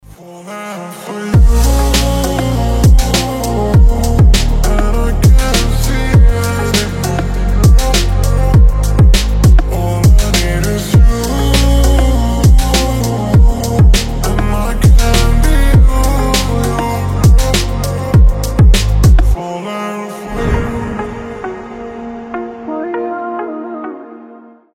trap , восточные
релакс